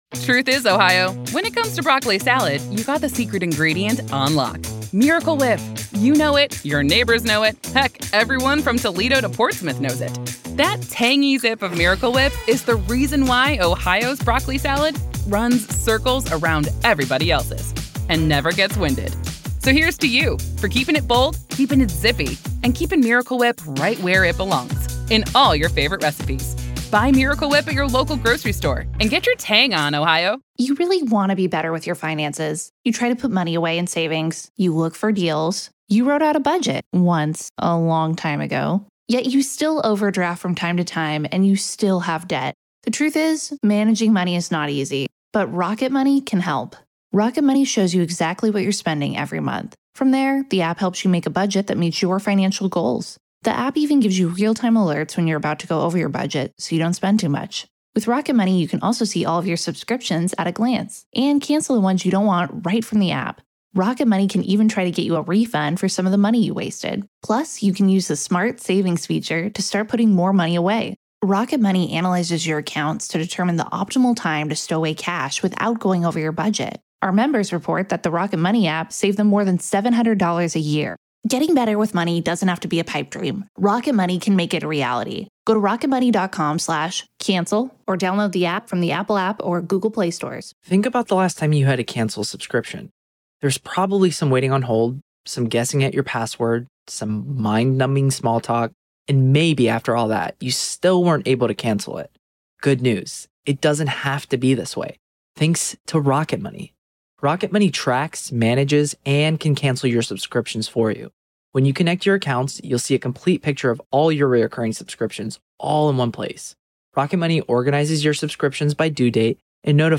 LIVE COURTROOM COVERAGE — NO COMMENTARY
There is no editorializing, no added narration, and no commentary — just the court, the attorneys, the witnesses, and the judge.